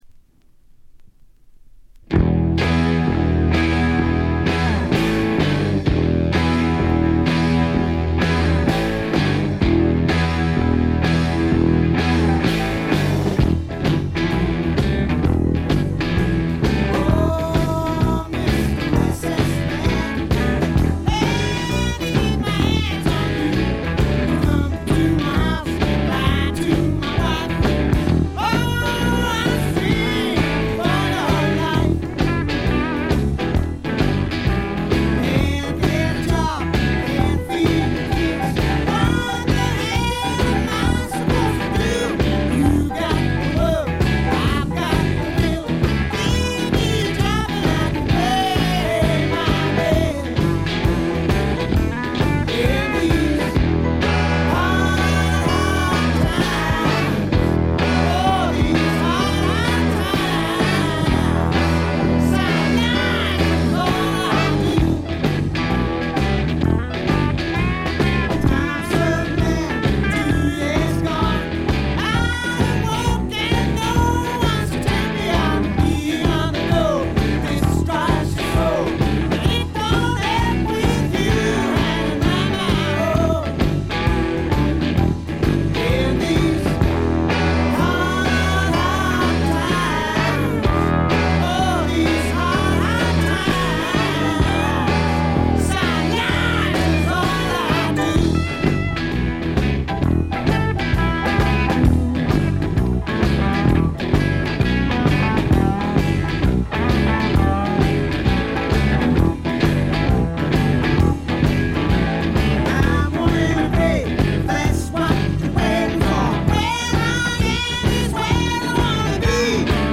見た目に反してところどころでチリプチが出ますが大きなノイズはありません。
それに加えて激渋ポップ感覚の漂うフォークロック作品です。
この人の引きずるように伸びのあるヴォーカルは素晴らしいです。
試聴曲は現品からの取り込み音源です。